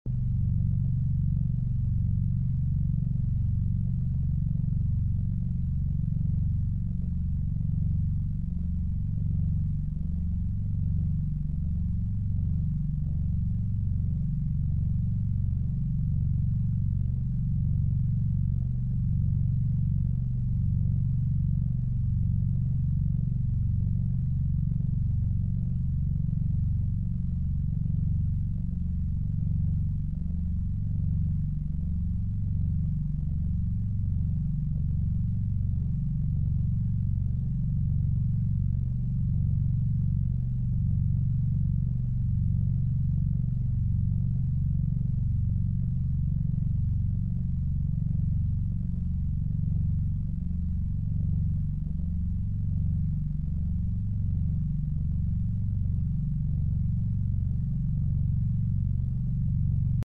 #40hzbinauralbeats